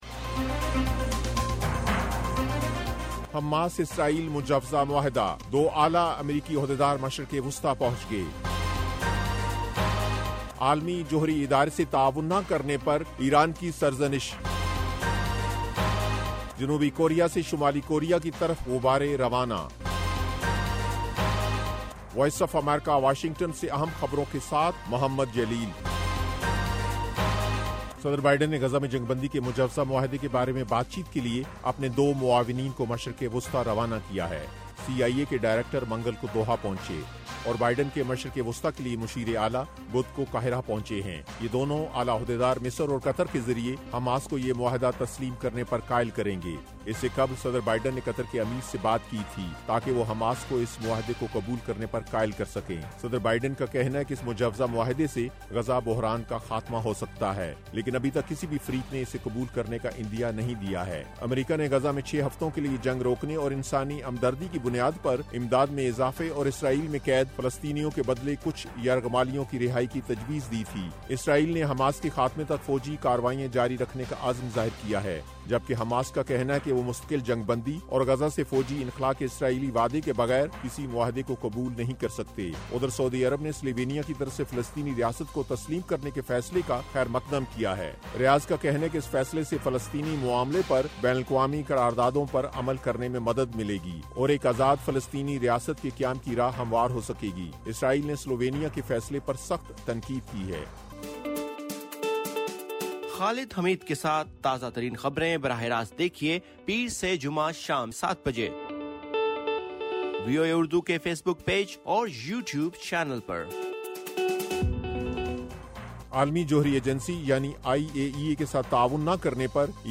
ایف ایم ریڈیو نیوز بلیٹن: شام 5 بجے